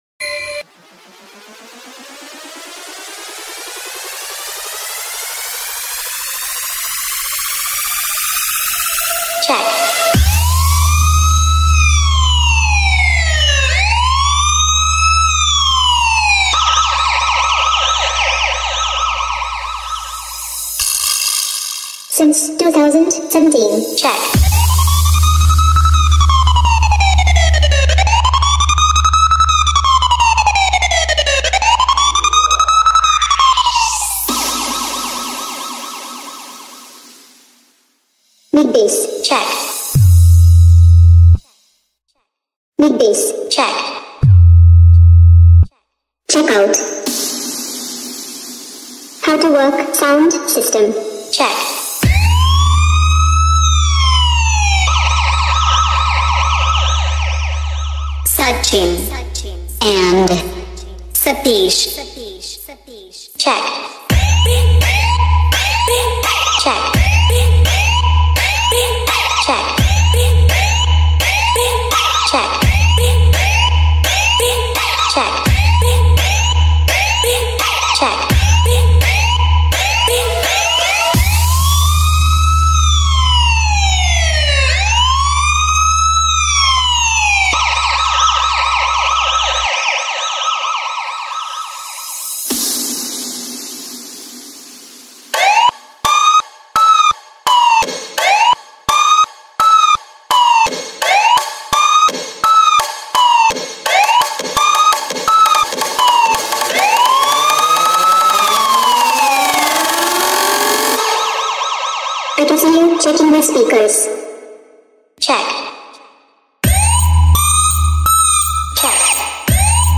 آهنگ پلیسی شوتی سیستمی ریمیکس معروف